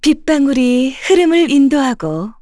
Laudia-Vox_Skill2_kr.wav